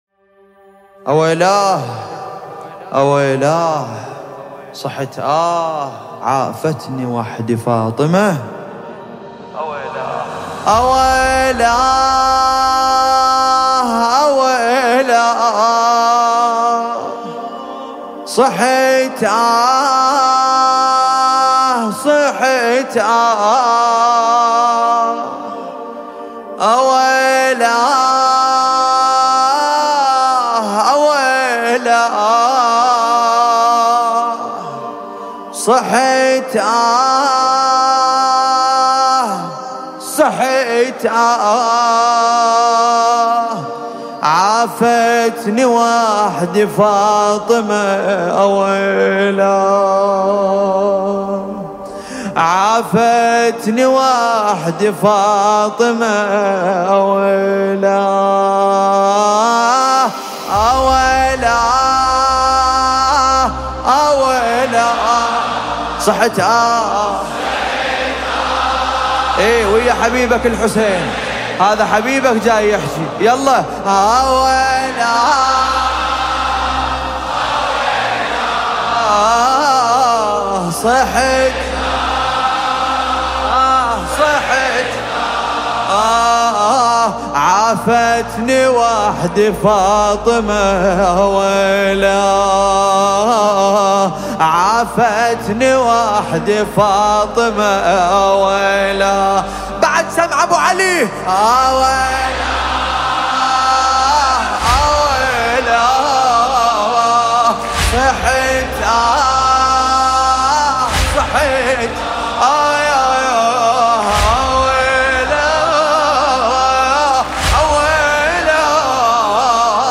نوحه عربی دلنشین